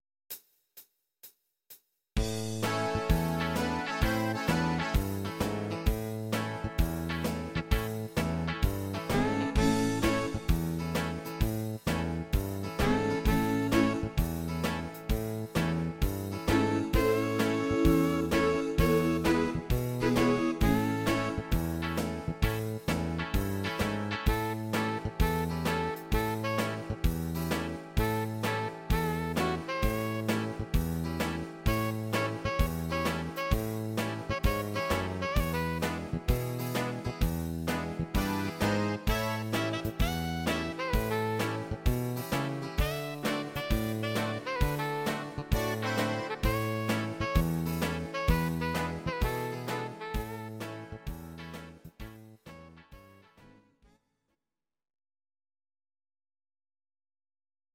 Please note: no vocals and no karaoke included.
Your-Mix: Country (822)